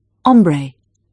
Hur uttalas ordet människa ? [ˈmɛnɪɧa]